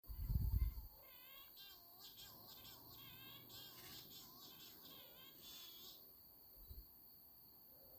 Con il buio più totale mentre facevamo le foto, abbiamo sentito degli strani e ripetuti gemiti che provenivano dal
Dopo un primo momento di inquietudine, abbiamo capito che questi (direi anche poco rassicuranti) gemiti, sono della
Berta Maggiore, un uccello che nidifica in cavità e scogliere di rocce ripide.
berta_maggiore_gemito_1.mp3